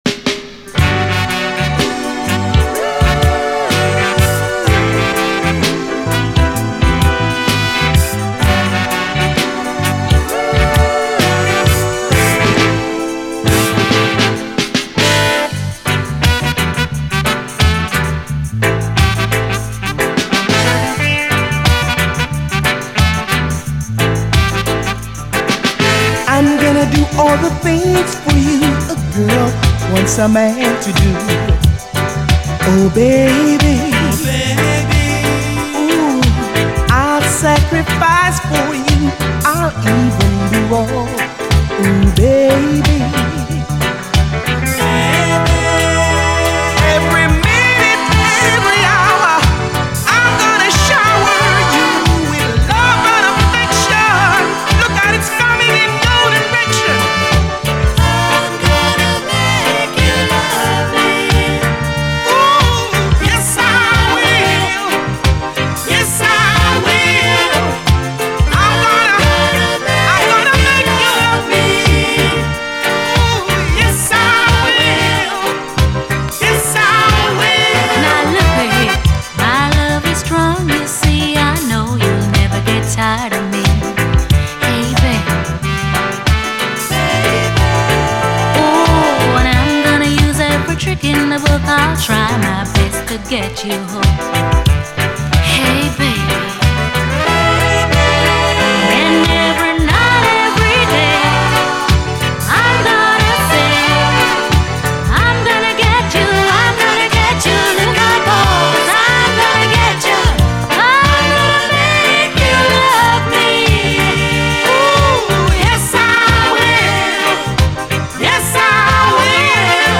REGGAE
インストも最高です。